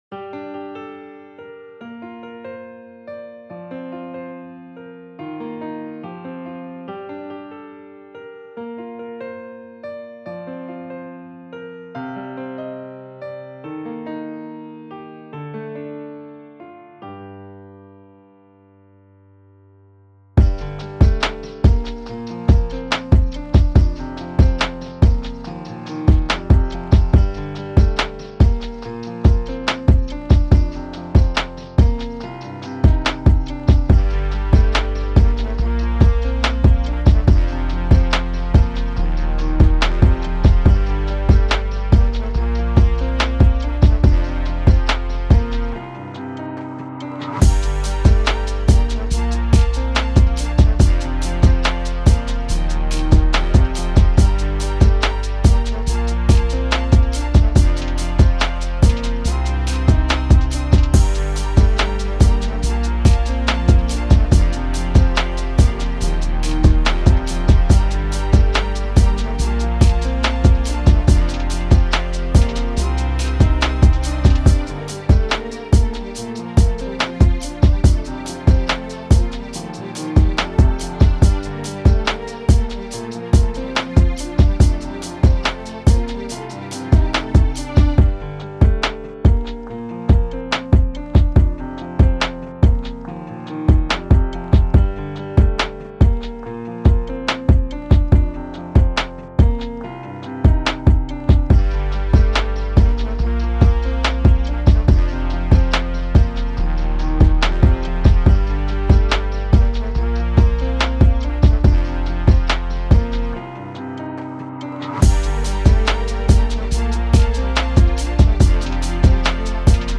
Pop, R&B
G Min